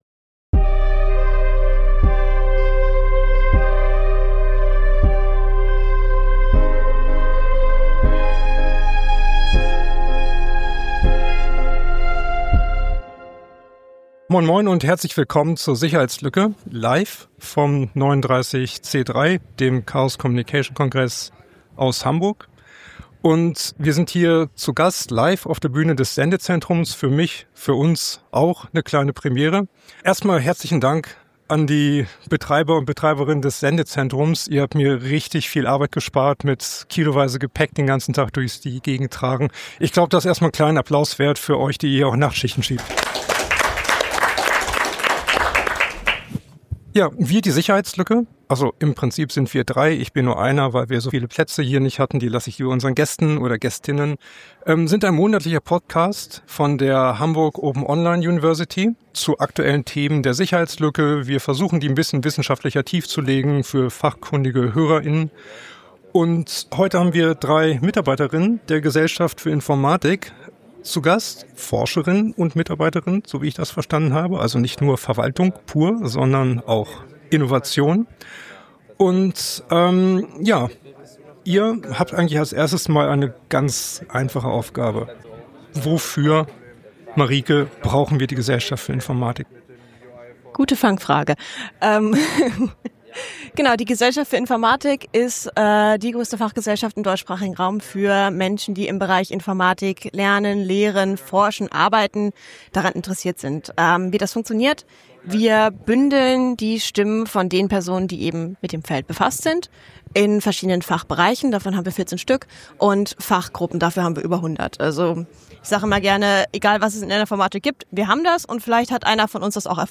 Wir senden in diesem Jahr jeden Tag live vom 39C3, dem 39. Chaos Communication Congress in Hamburg. In dieser Folge sprechen wir auf der Bühne des Sendezentrums über die Vorträge von Tag 1, die wir empfehlen möchten, und darüber, weshalb wir sie interessant und relevant finden.